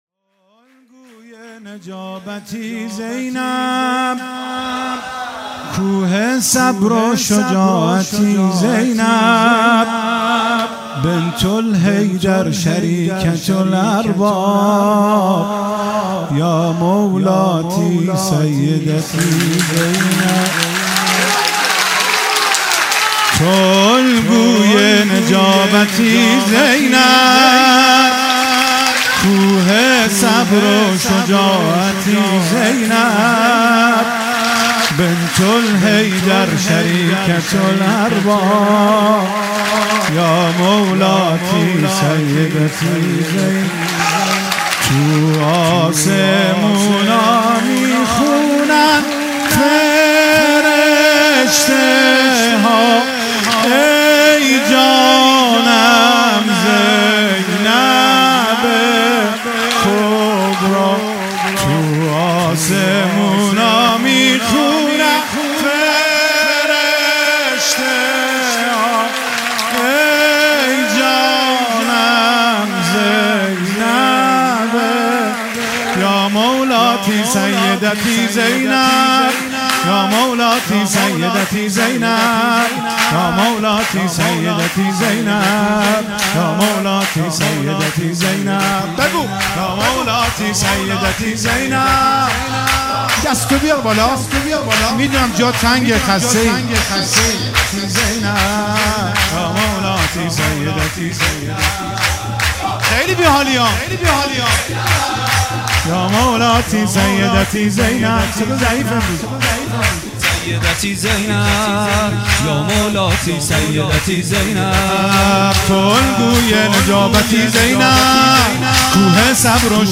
ولادت حضرت زینب کبری(س)